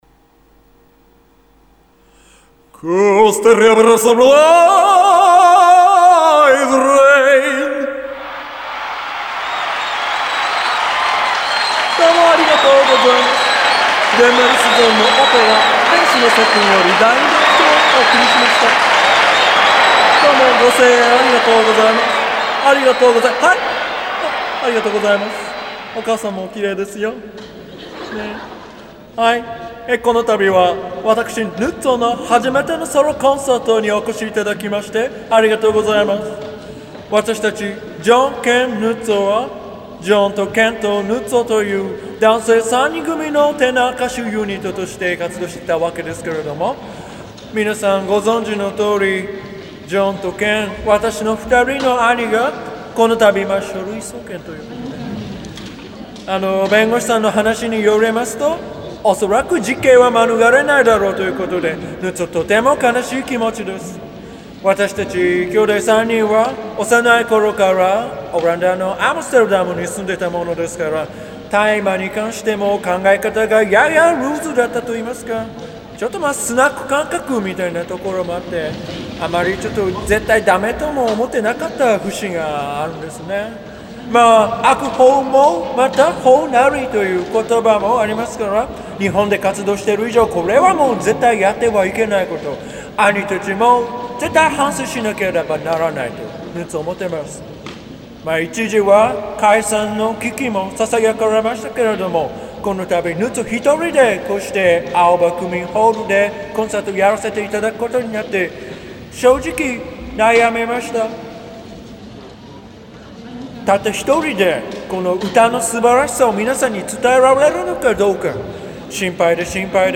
愛する兄を失ったテノール歌手が再起を賭けて歌います
残された末弟のヌッツォは、再起を賭けて祖国オランダの民謡をベースにしたという歌を披露するのだが…。